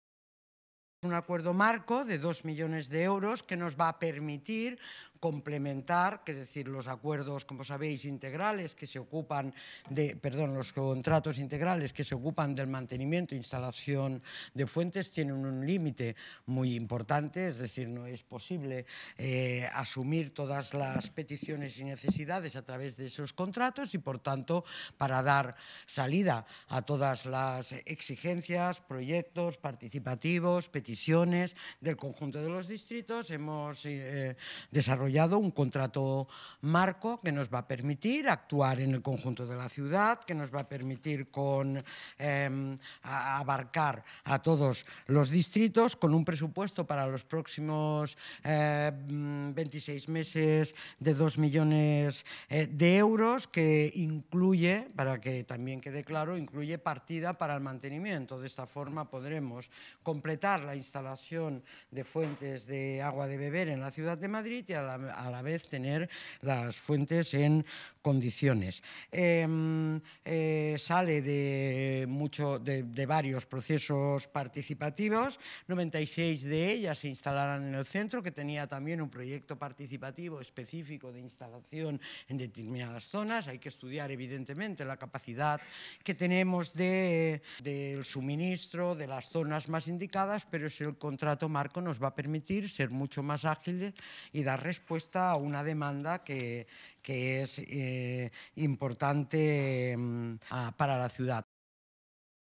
Nueva ventana:Ines Sabanes, delegada Medio Ambiente y Movilidad. Respuesta a peticiones ciudadanas